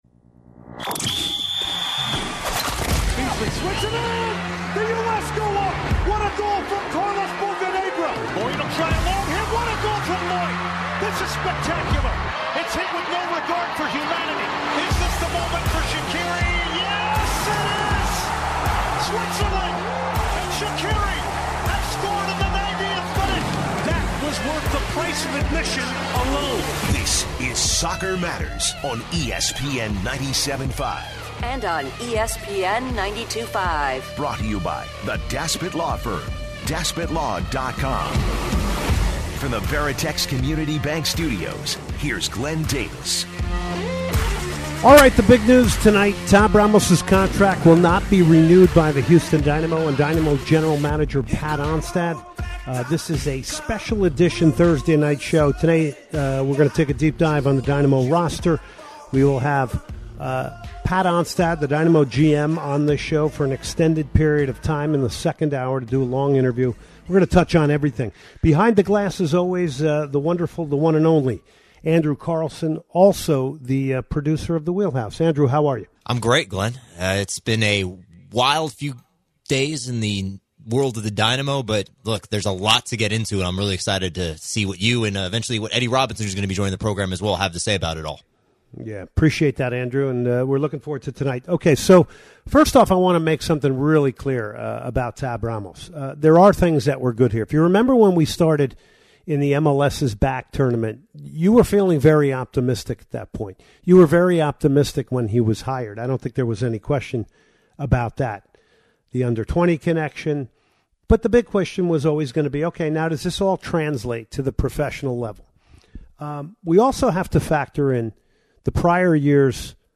he takes some calls from the impassioned Dynamo fanbase.